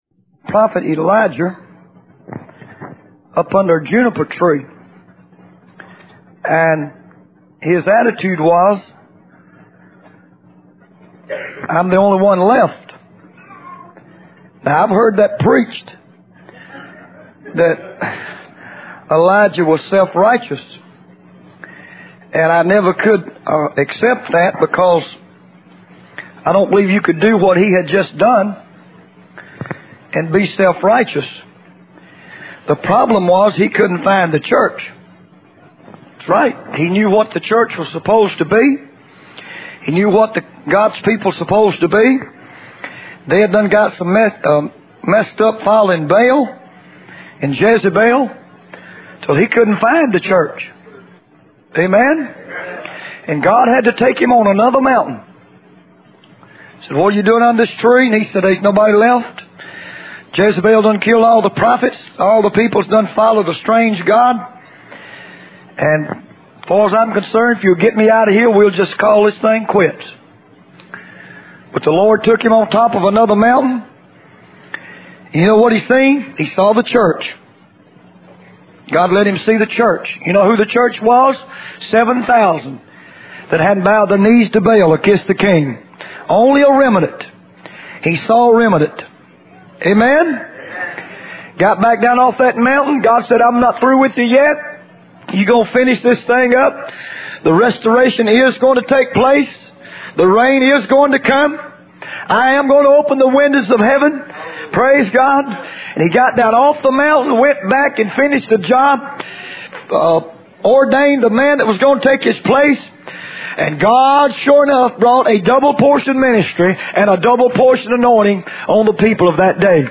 In this sermon, the preacher emphasizes the importance of having a transformed and circumcised heart in the church.